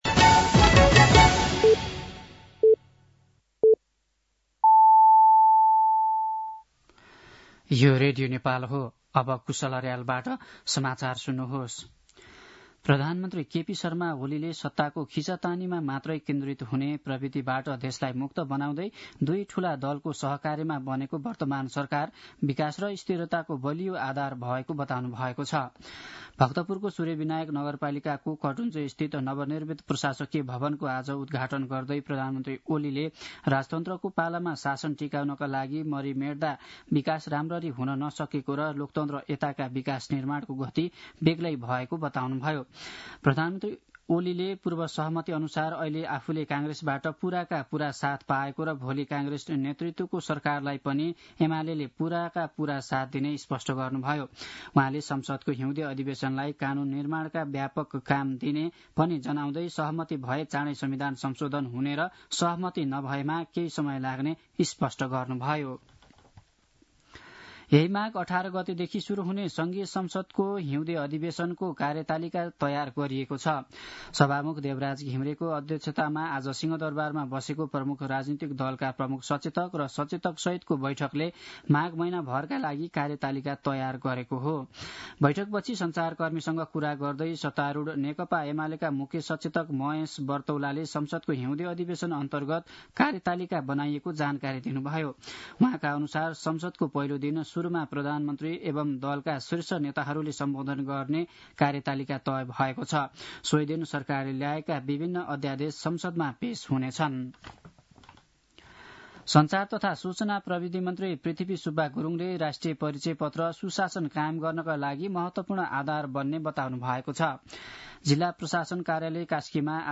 साँझ ५ बजेको नेपाली समाचार : १५ माघ , २०८१
5-pm-nepali-news-10-14.mp3